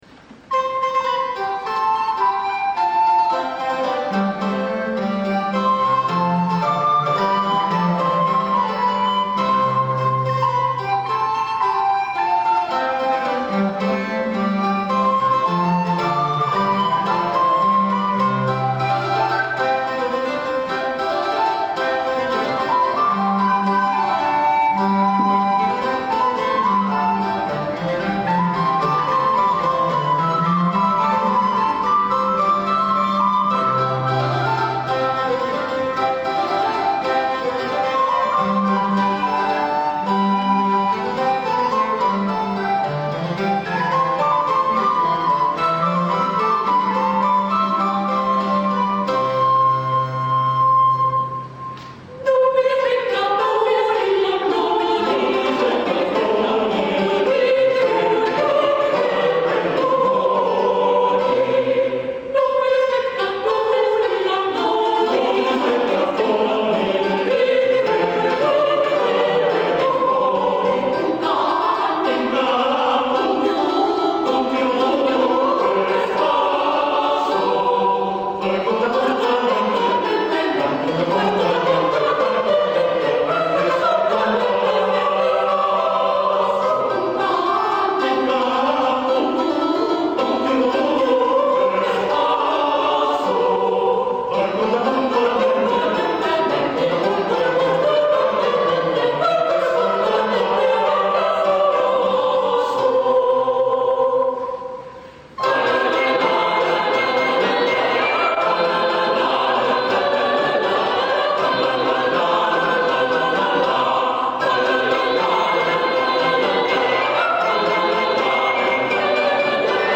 Si tratta di due brani della sua terza raccolta di madrigali a cinque voci, pubblicata nel 1608 come Festino del giovedì grasso, in genere sempre eseguiti in coppia perché entrambi legati allo stesso soggetto.
La musica si presenta in tre sezioni, la Capricciata a tre parti che introduce il Contrappunto a cinque: